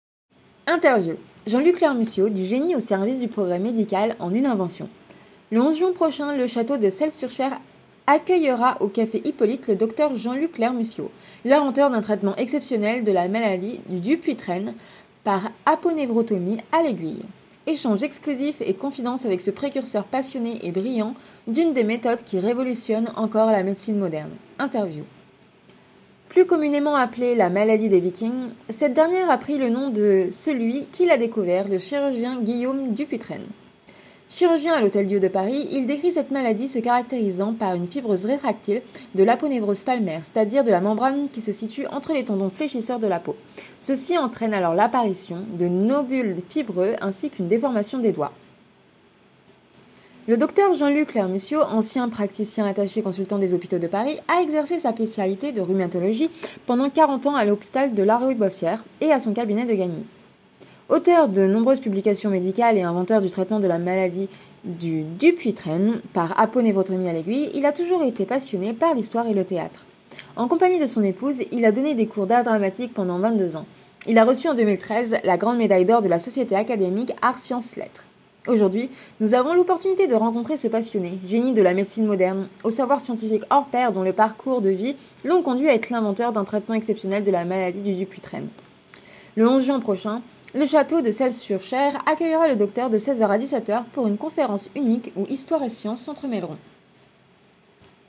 Interview
Conférence au château de Selles-sur-Cher. La maladie de Dupuytren.